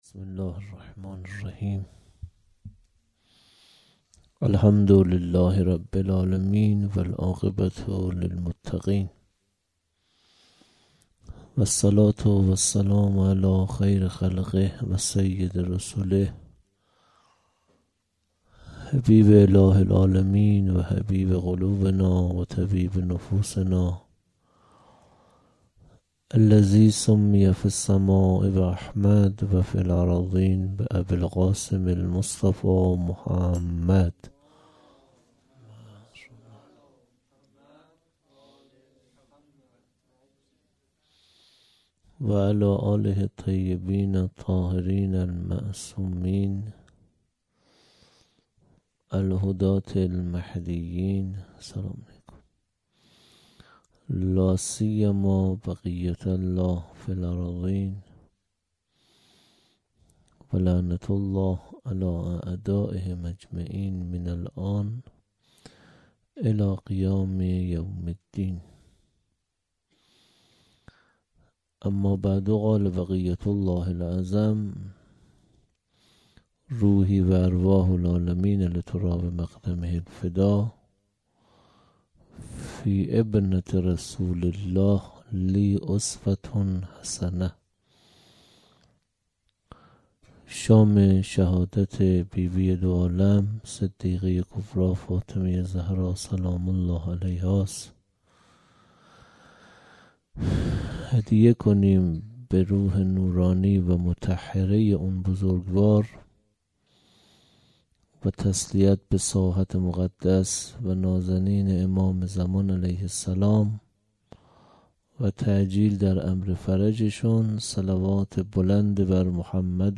1-shab4-sokhanrani.mp3